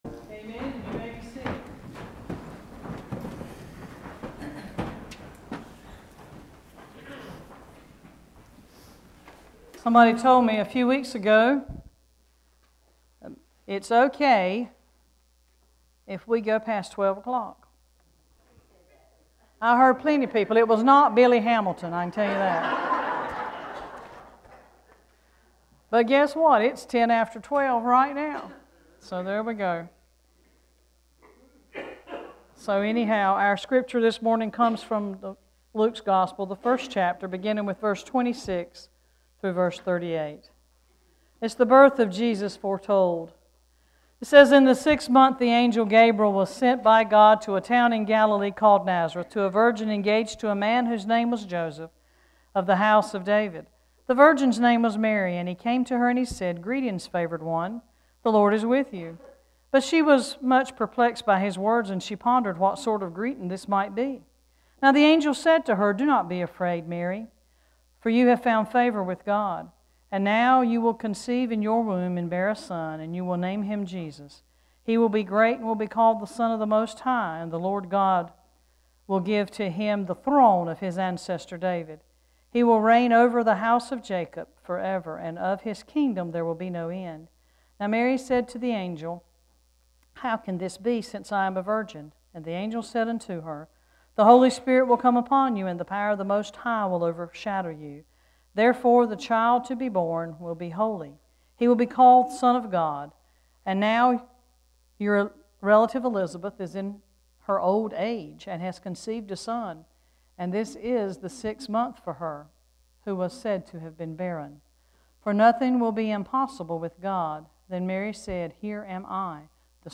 This weeks scripture and sermon:
scripture is included in sermon file below